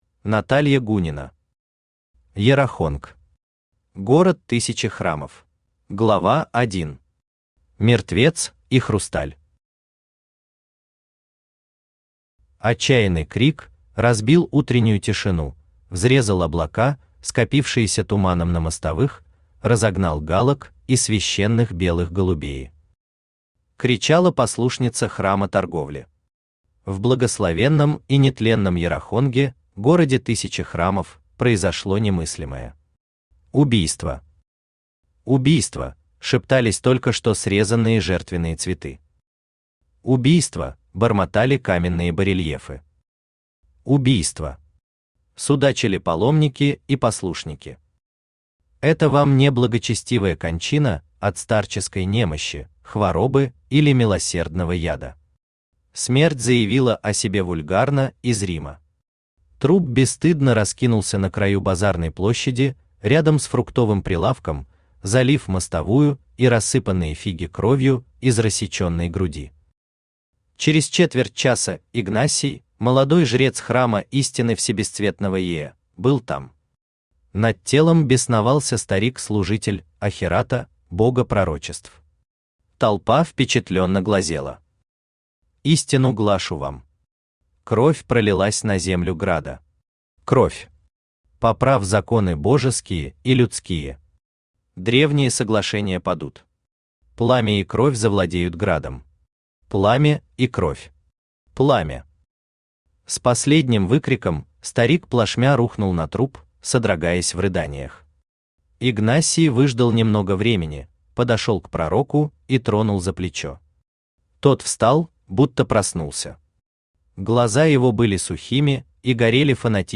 Аудиокнига Йарахонг. Город тысячи храмов | Библиотека аудиокниг
Город тысячи храмов Автор Наталья Гунина Читает аудиокнигу Авточтец ЛитРес.